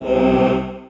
Choir.wav